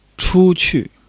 chu1 qu4.mp3